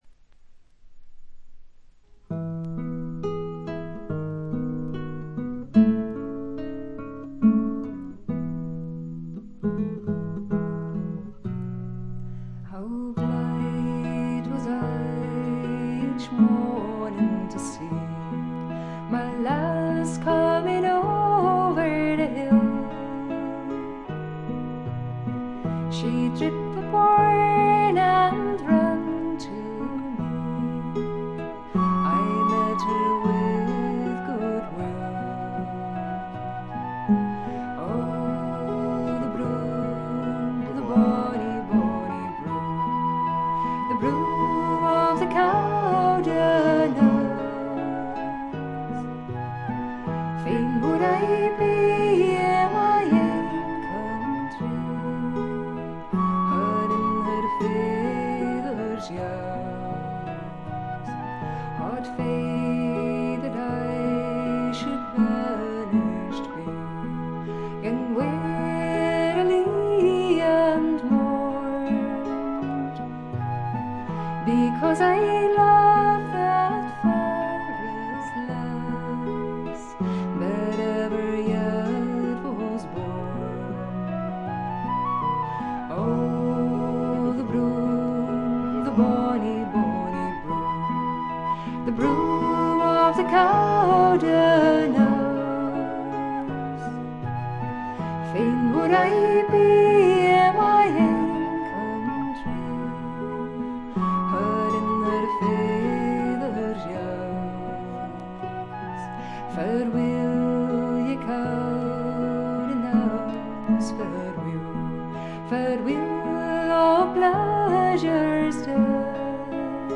オランダのトラッド・フォーク・グループ
試聴曲は現品からの取り込み音源です。
Vocals, Flute, Spoons